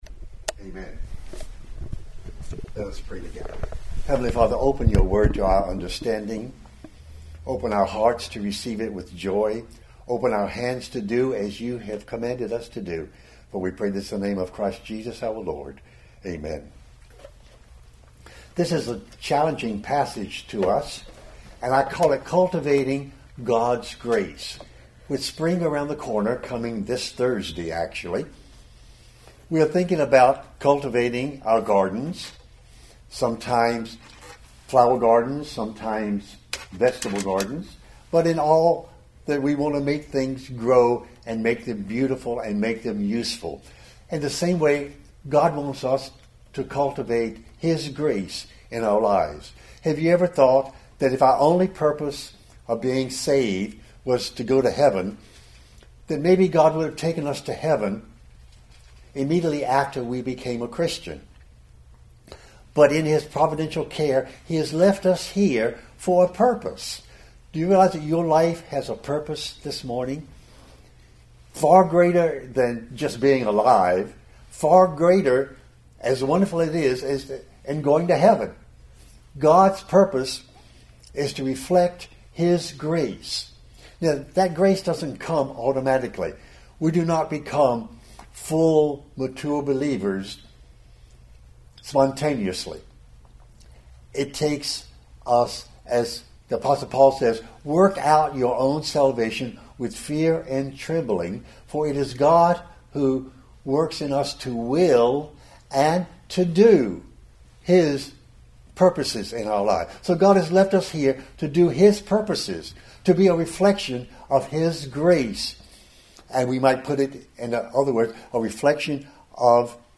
March 14, 2020 2 Peter – Covenant Living series Weekly Sunday Service Save/Download this sermon 2 Peter 1:5-8 Other sermons from 2 Peter 5 For this very reason, make every effort […]